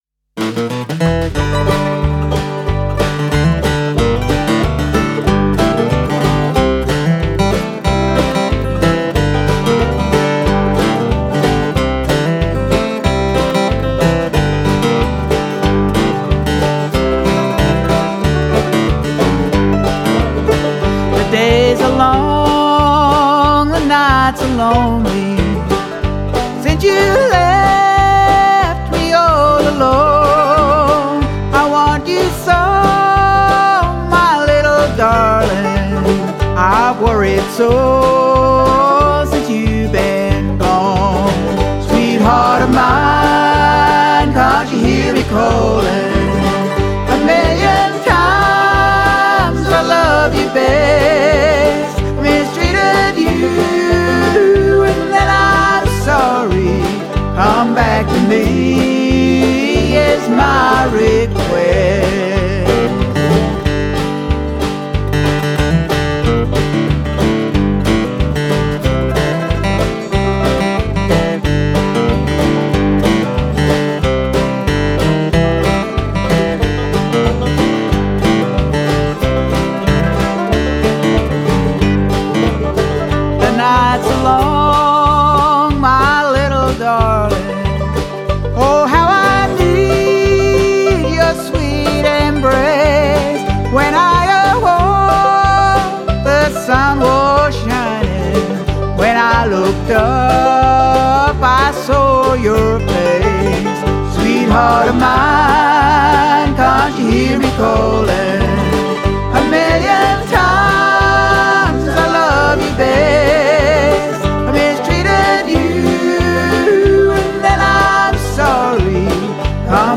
timeless bluegrass classic
lead vocals, acoustic guitar work and a sprinkle of mandolin
banjo picking
group harmony vocal combinations